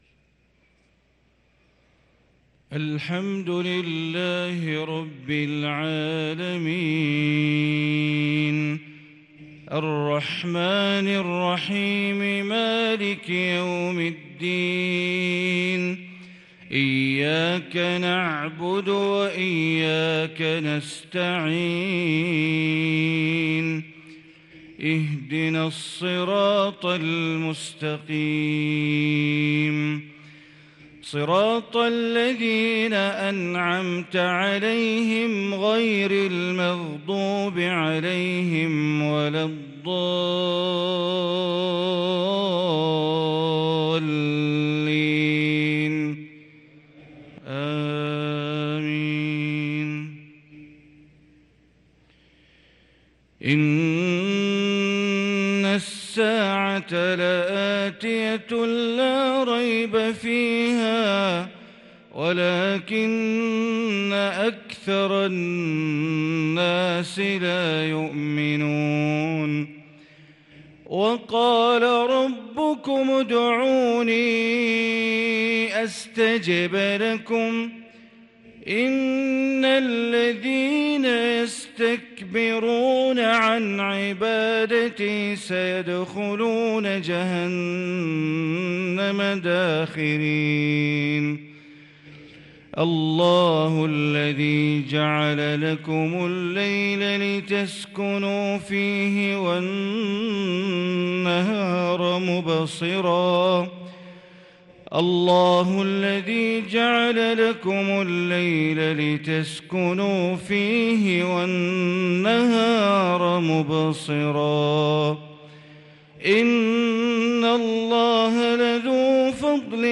صلاة المغرب للقارئ بندر بليلة 16 ذو الحجة 1443 هـ
تِلَاوَات الْحَرَمَيْن .